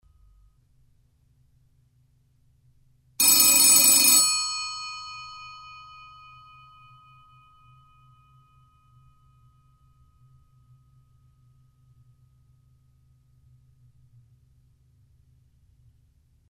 Hier vorgestellt wird Ihnen der Klang des schönsten aller Telefone: Des W28.
1. Das Telefon läutet (sprich: es klingelt). Gut zu hören sind die beiden hell klingenden Metallglocken, welche je eine andere Tonhöhe haben (kling - klong):
W28_klingelt.mp3